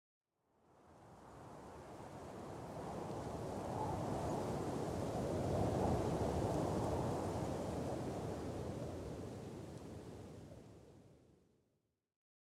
Minecraft Version Minecraft Version snapshot Latest Release | Latest Snapshot snapshot / assets / minecraft / sounds / ambient / nether / soulsand_valley / mood2.ogg Compare With Compare With Latest Release | Latest Snapshot